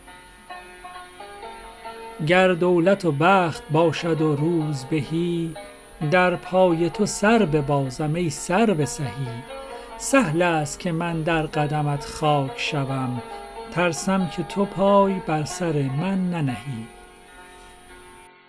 رباعی شمارهٔ ۱۴۶ به خوانش